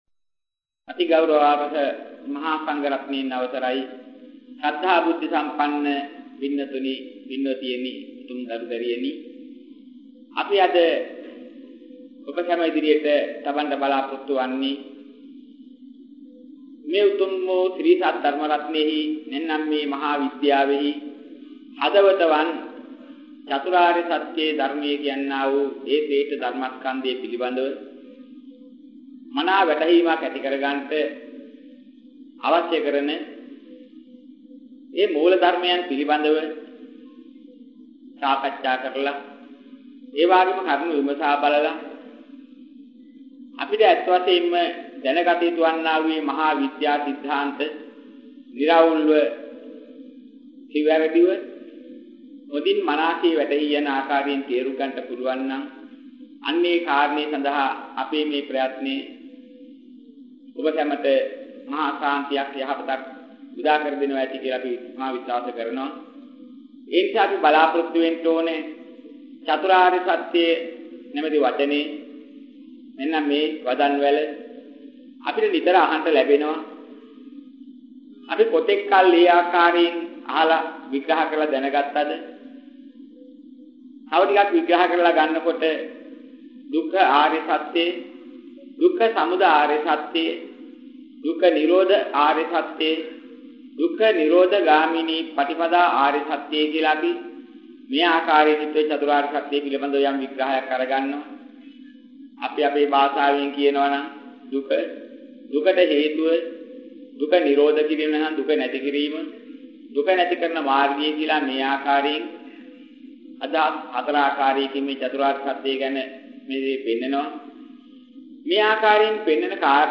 වෙනත් බ්‍රව්සරයක් භාවිතා කරන්නැයි යෝජනා කර සිටිමු 49:03 10 fast_rewind 10 fast_forward share බෙදාගන්න මෙම දේශනය පසුව සවන් දීමට අවැසි නම් මෙතැනින් බාගත කරන්න  (20 MB)